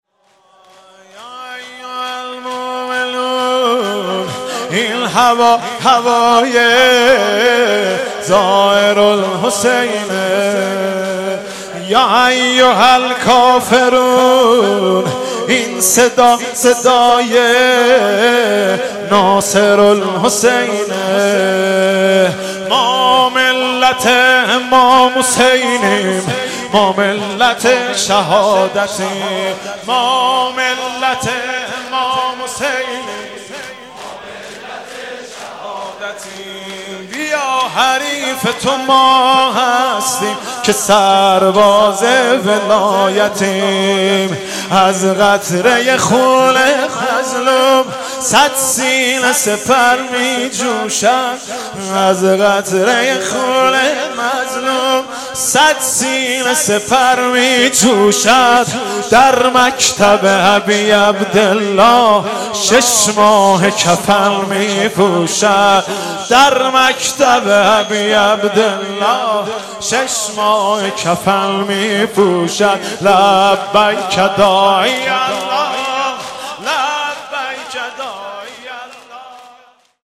محرم 99 - شب اول - شور - یا ایها المومنون این هوا هوای زائر الحسینه